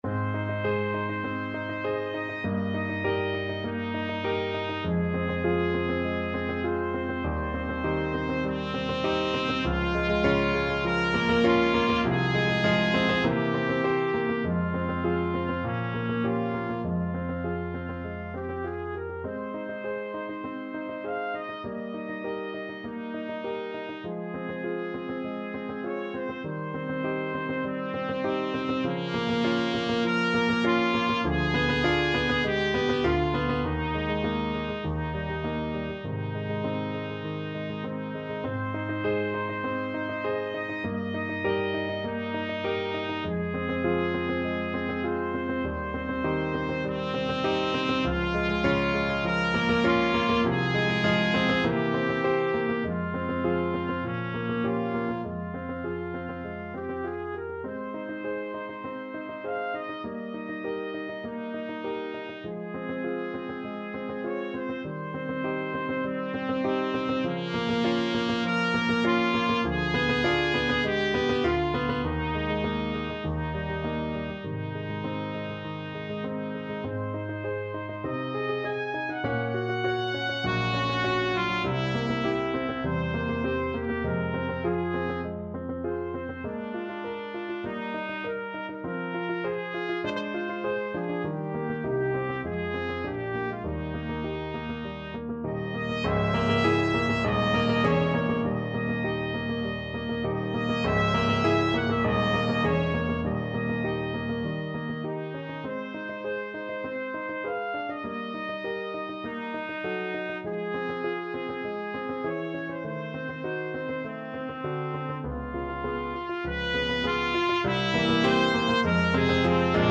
Ab major (Sounding Pitch) Bb major (Trumpet in Bb) (View more Ab major Music for Trumpet )
Allegro moderato (View more music marked Allegro)
4/4 (View more 4/4 Music)
Ab4-Ab6
Trumpet  (View more Intermediate Trumpet Music)
Classical (View more Classical Trumpet Music)